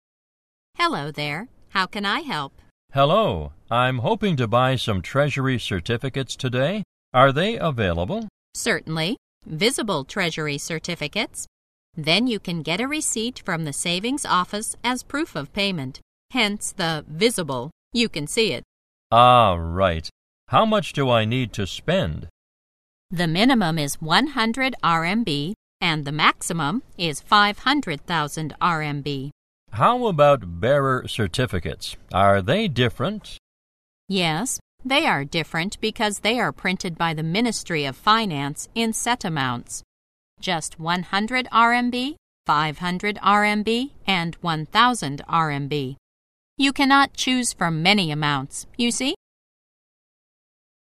在线英语听力室银行英语情景口语 第81期:外汇业务 代理债券业务(1)的听力文件下载, 《银行英语情景口语对话》,主要内容有银行英语情景口语对话、银行英语口语、银行英语词汇等内容。